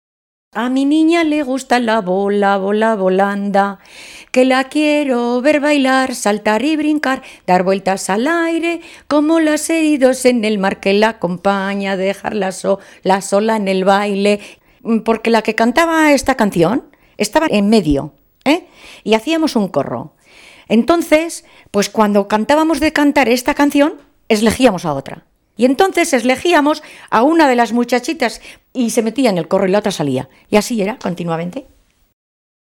Clasificación: Corros y bailes
Lugar y fecha de recogida: Calahorra, 13 de abril de 2003